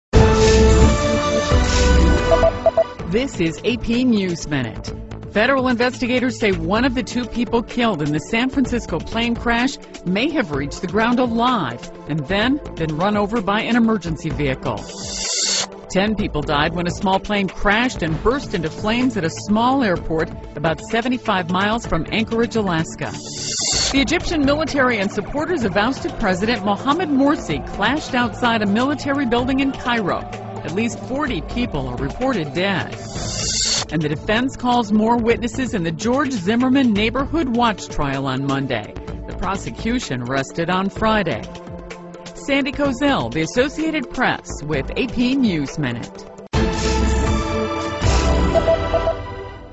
在线英语听力室美联社新闻一分钟 AP 2013-07-11的听力文件下载,美联社新闻一分钟2013,英语听力,英语新闻,英语MP3 由美联社编辑的一分钟国际电视新闻，报道每天发生的重大国际事件。电视新闻片长一分钟，一般包括五个小段，简明扼要，语言规范，便于大家快速了解世界大事。